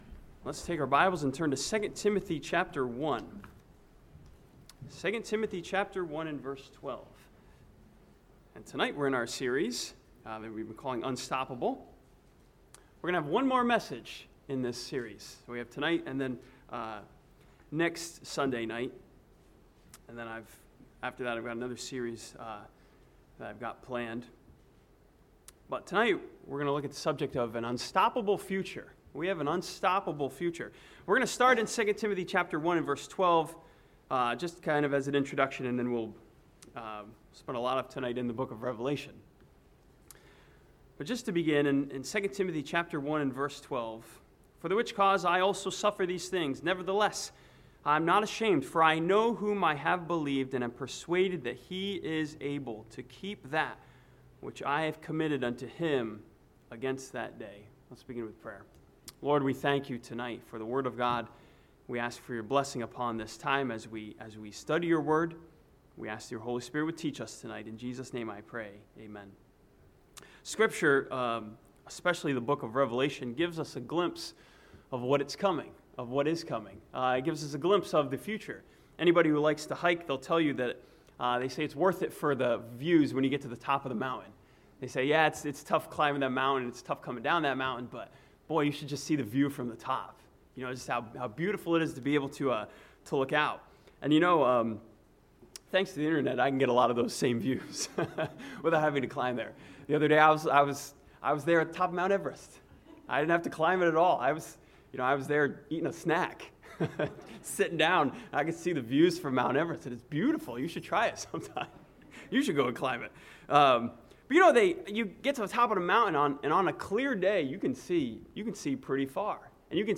This sermon begins in 2 Timothy 1:12 an looks toward our unstoppable future that is promised and guaranteed by God.